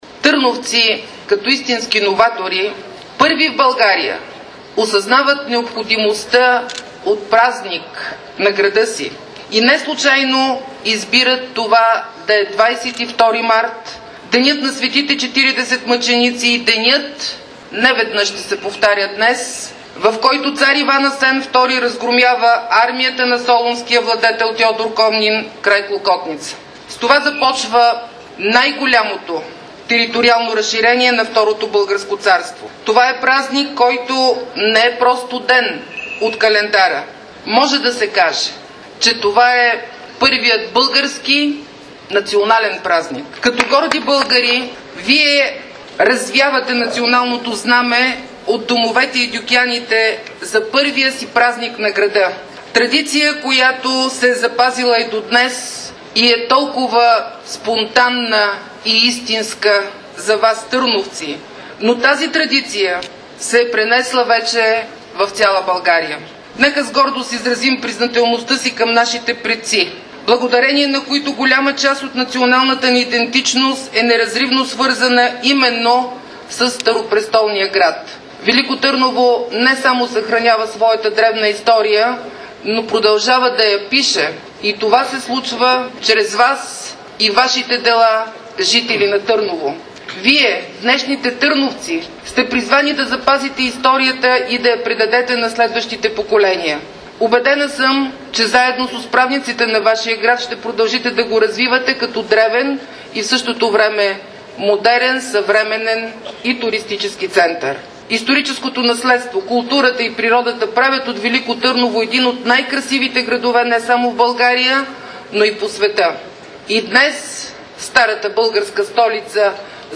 Слово на Цецка Цачева